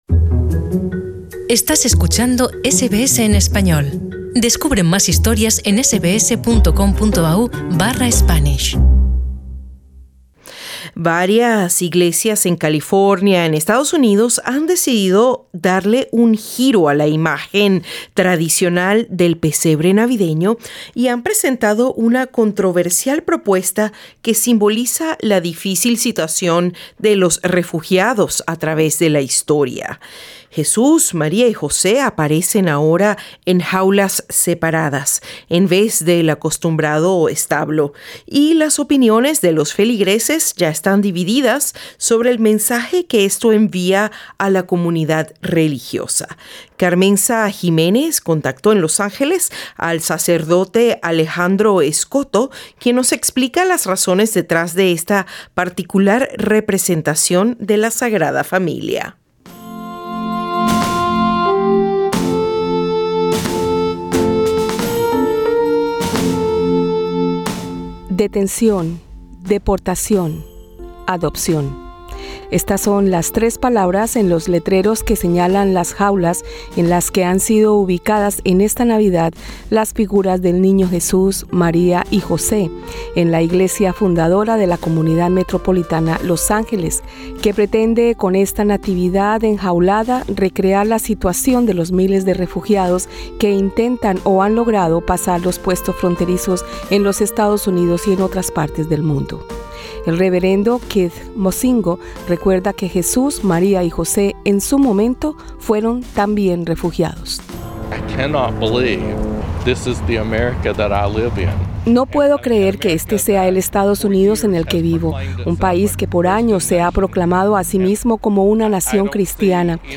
en entrevista con SBS español